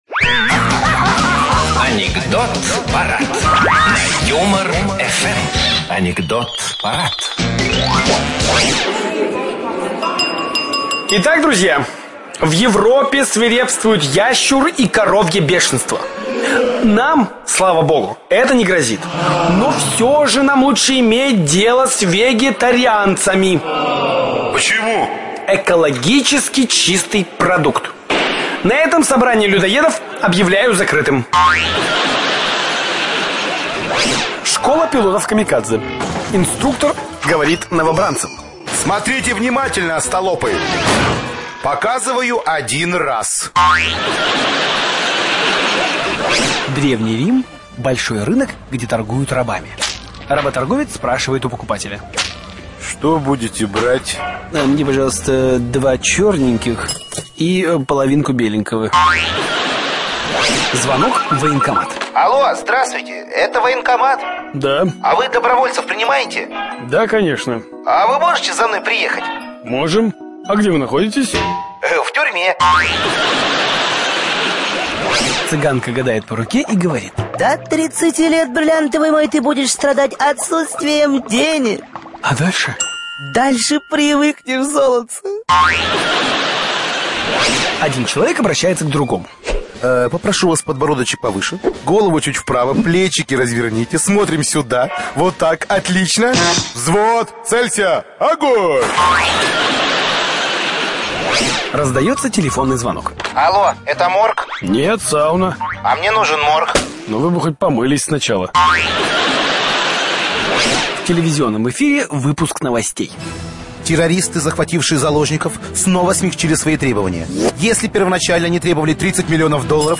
yumor_fm_-_anekdoty_chernyj_yumor.mp3